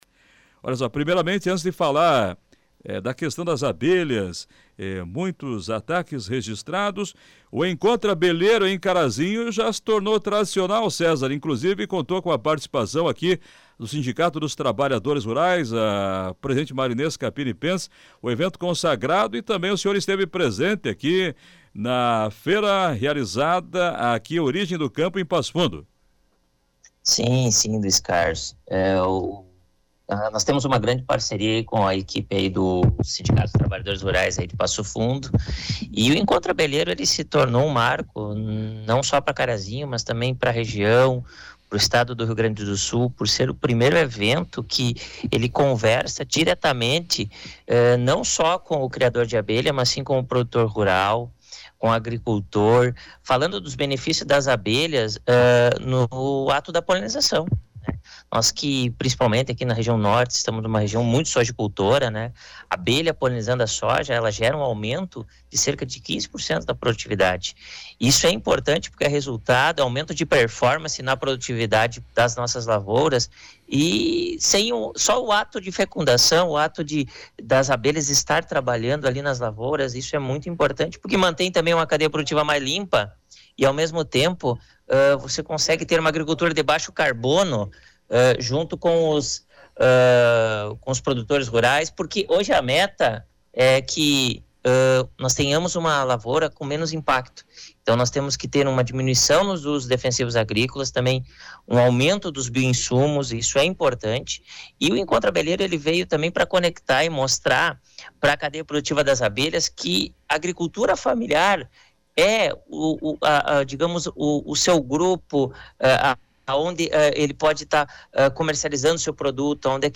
No programa Comando Popular, da Rádio Planalto News (92.1), desta quinta-feira, 15, foi tratado sobre a importância das abelhas para o desenvolvimento regional.